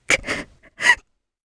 Hilda-Vox_Sad_jp_d.wav